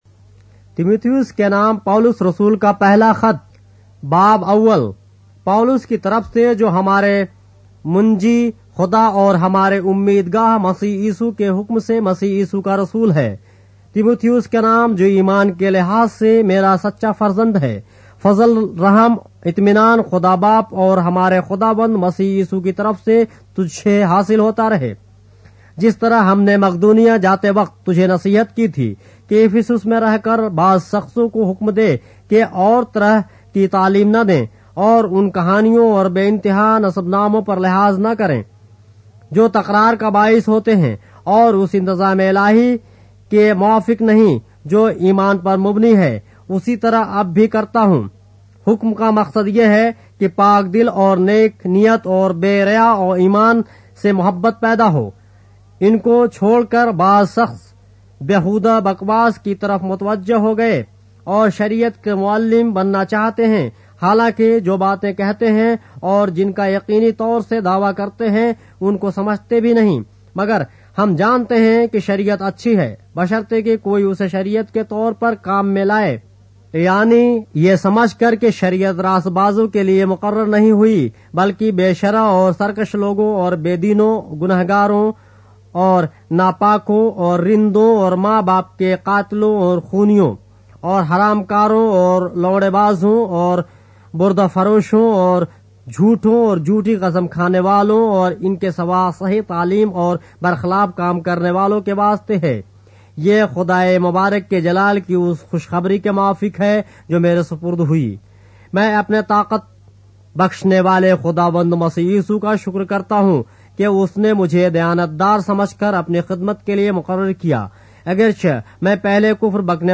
اردو بائبل کے باب - آڈیو روایت کے ساتھ - 1 Timothy, chapter 1 of the Holy Bible in Urdu